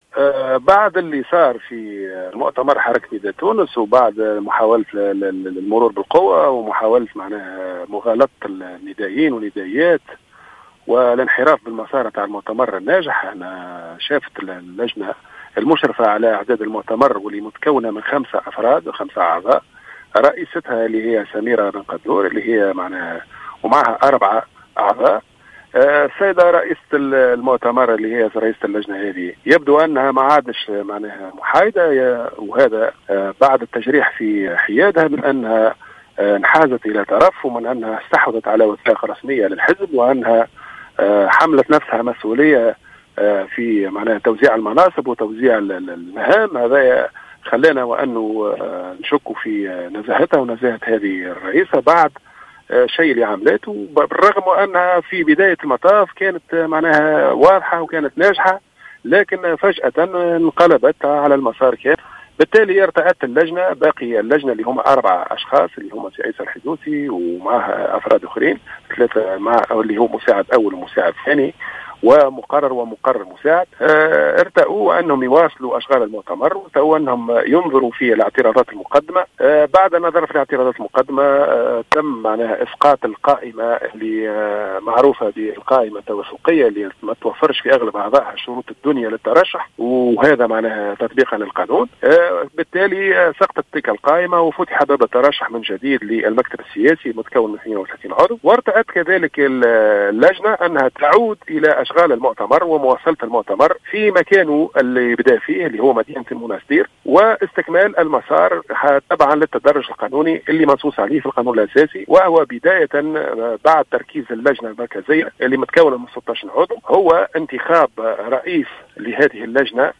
أكد القيادي في حركة نداء تونس، المنجي الحرباوي في تصريح للجوهرة "اف ام" أنه سيقع استنئاف أشغال المؤتمر الانتخابي للحزب بمدينة المنستير مساء غد السبت.